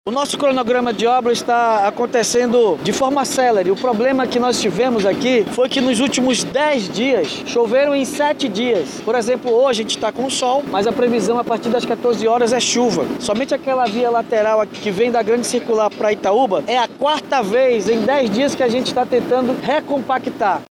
Em uma entrevista coletiva, concedida à imprensa, o Chefe do Executivo Municipal disse que a instabilidade climática atrasou o cronograma de conclusão da obra.